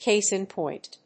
cáse in póint